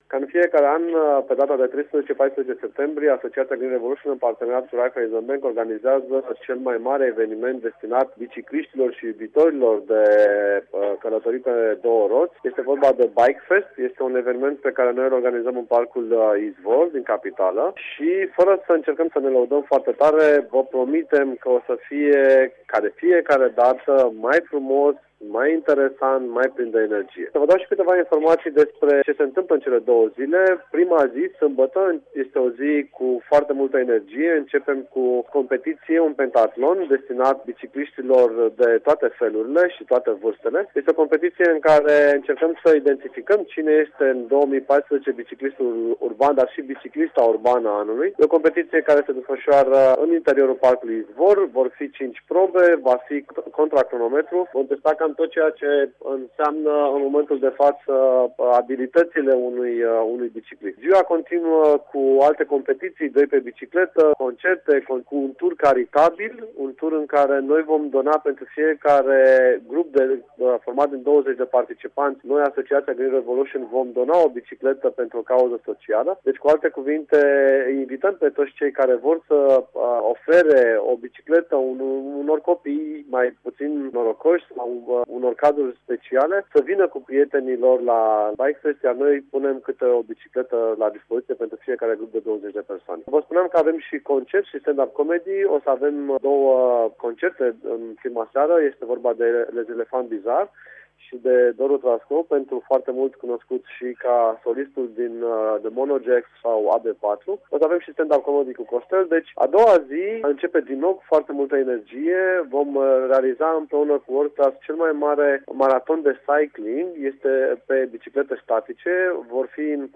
Atmosfera extraordinara la BikeFest 2014!